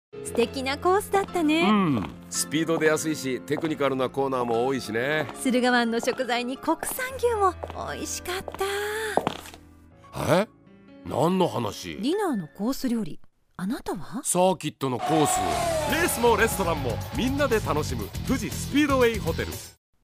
第18回SBSラジオCMコンテスト